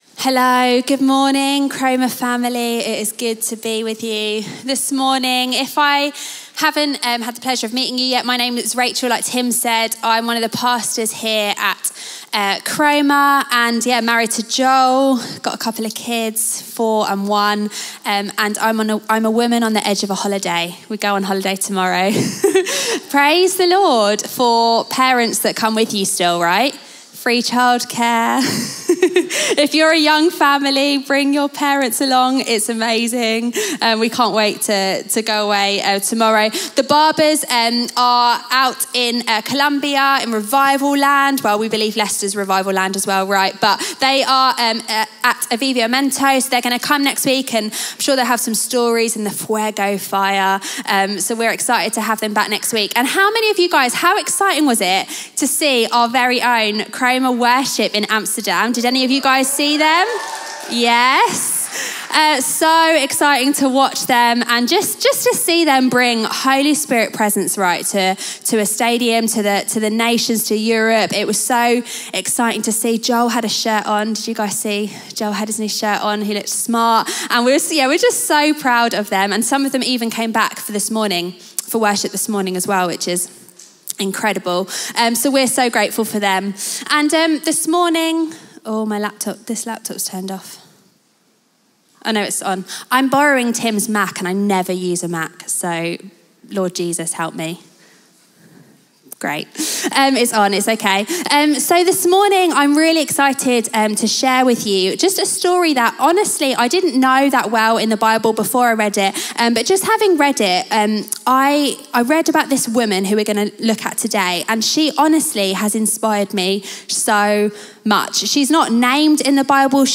Chroma Church - Sunday Sermon The Widow’s Faith Jul 04 2023 | 00:31:02 1x 00:00 / 00:31:02 Subscribe Share RSS Feed Share Link Embed